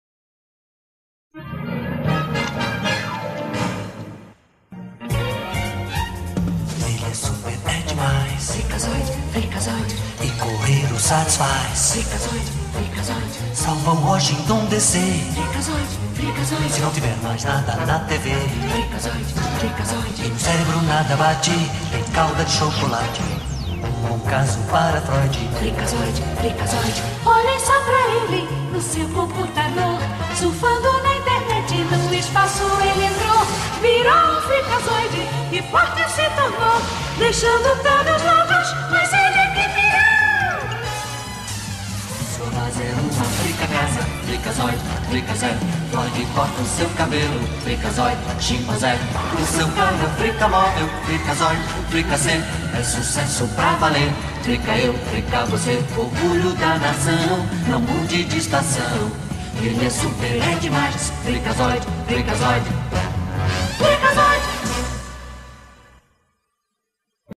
Então já vai ouvindo a música de abertura.